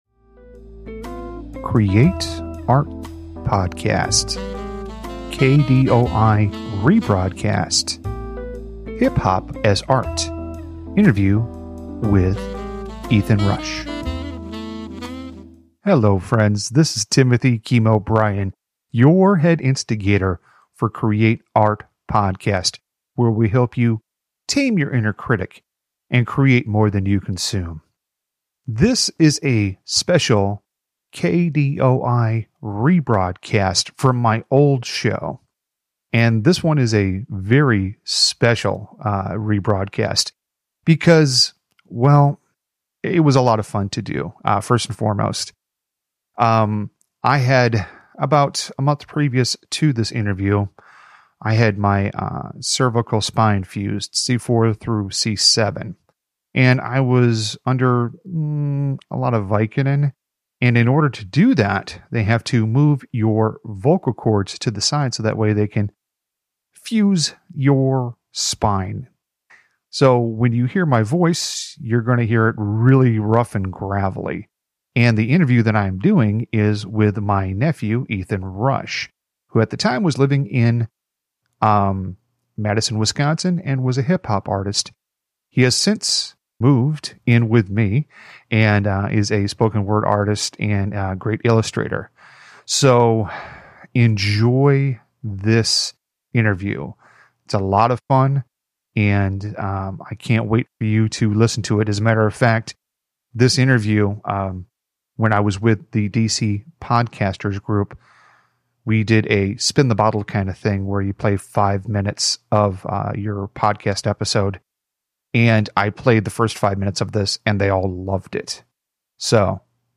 Hip Hop as Art This interview was a special one because about a month previous to it I had neck fusion surgery and in order to do that, the doctors had to move my vocal cords out of the way and my voice was very gruff.
This was a very fun interview as we had numerous tangents.